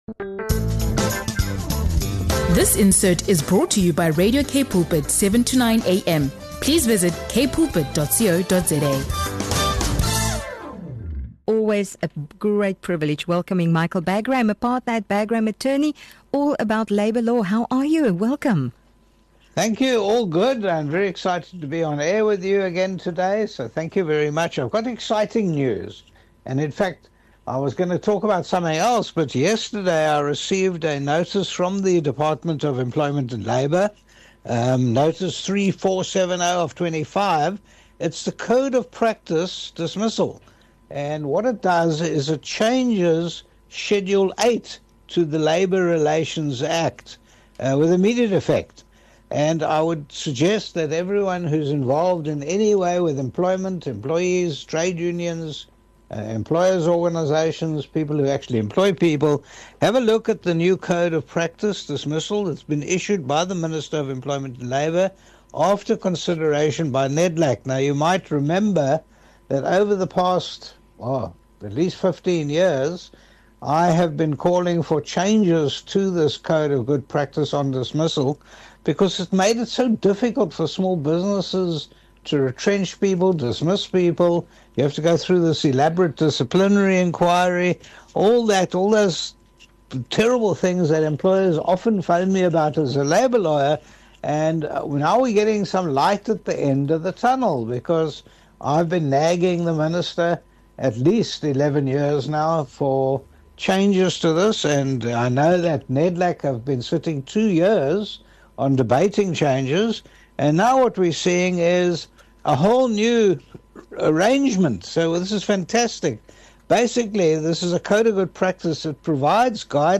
On Radio Cape Pulpit, labour law expert Michael Bagraim discusses the Department of Employment and Labour’s newly issued Code of Good Practice on Dismissal. The update to the Labour Relations Act simplifies dismissal procedures, especially for small businesses, by removing the need for lengthy disciplinary inquiries. Employers are now guided to ensure dismissals are based on fair reasons and fair processes without excessive red tape.